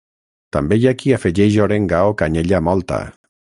Uitgespreek as (IPA) [ˈmɔl.tə]